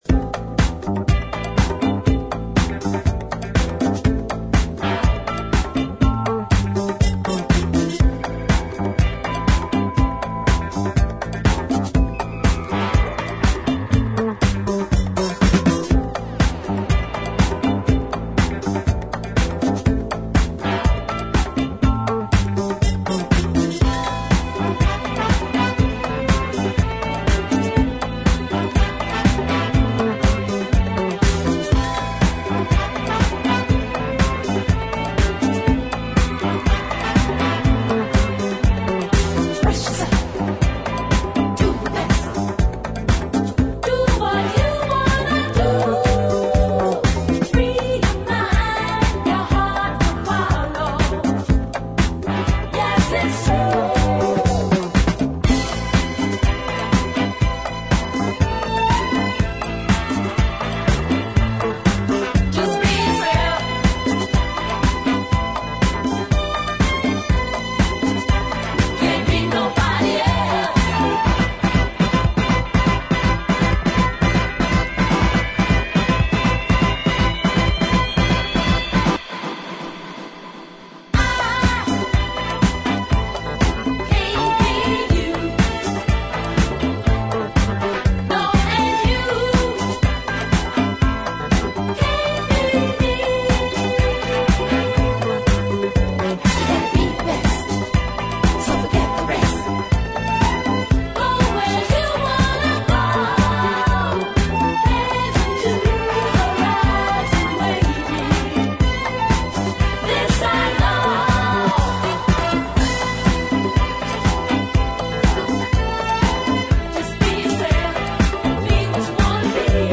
ジャンル(スタイル) DISCO / SOUL / FUNK / NU DISCO / RE-EDIT